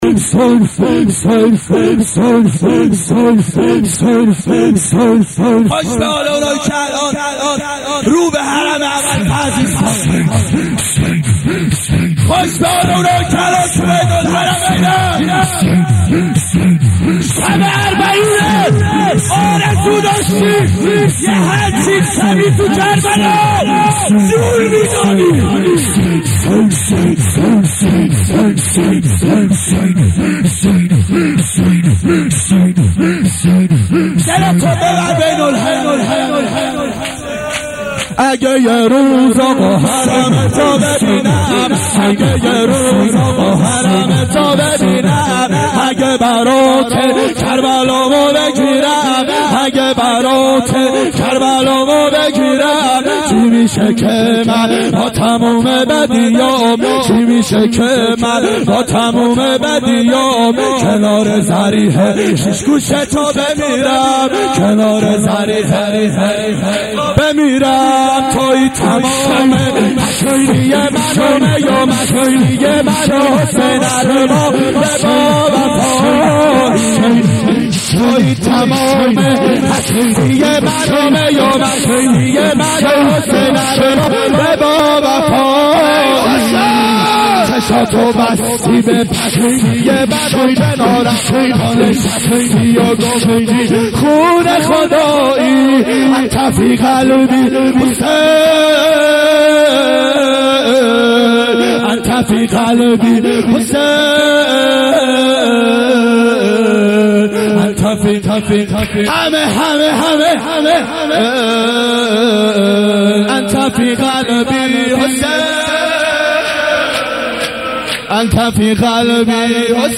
اربعین 86 هیئت متوسلین به امیرالمؤمنین حضرت علی علیه السلام